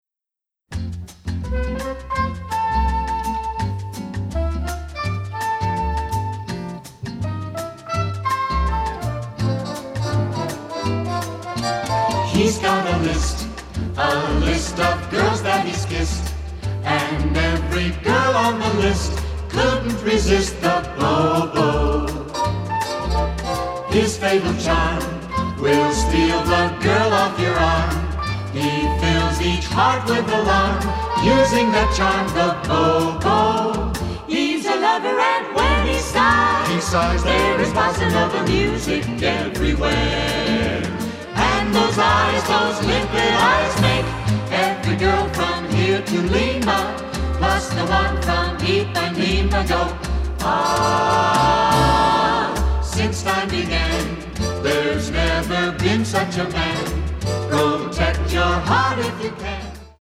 Vocal Version
catchy, lovely score